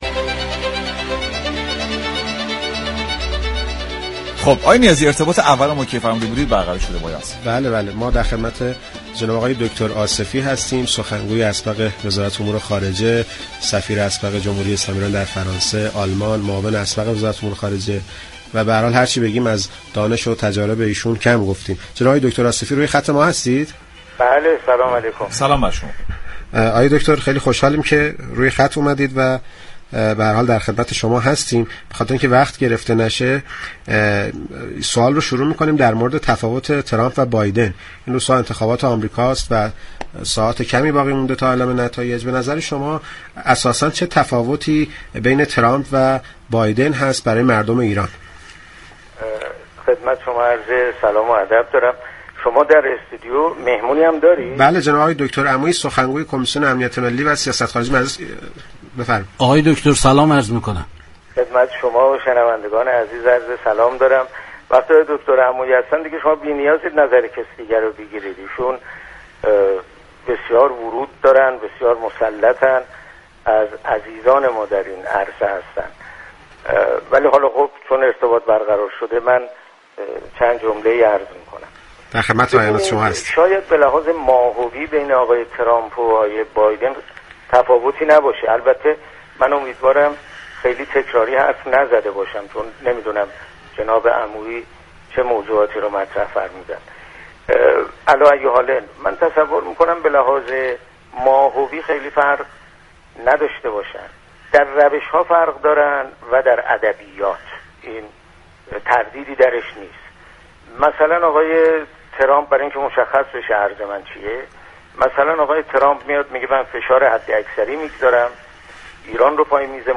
در اولین گفتگوی تلفنی برنامه سعادت آباد